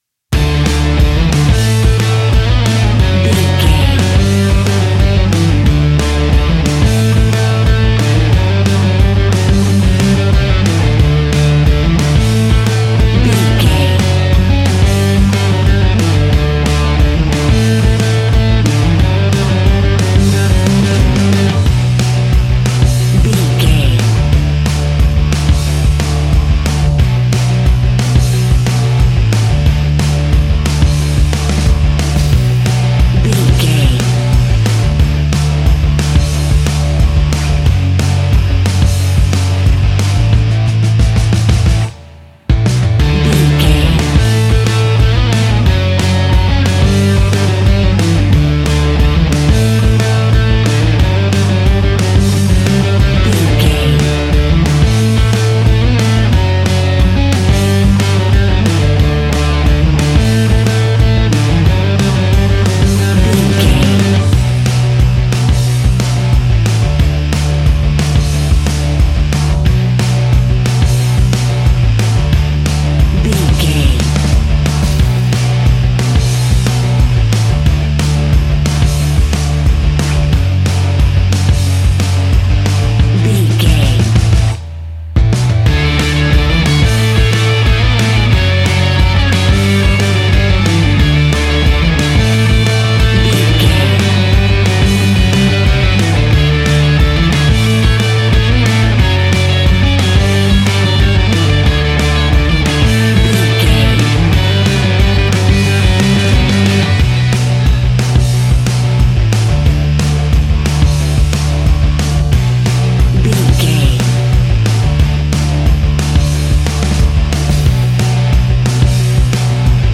Epic / Action
Fast paced
Ionian/Major
D
hard rock
distortion
punk metal
rock instrumentals
Rock Bass
Rock Drums
distorted guitars
hammond organ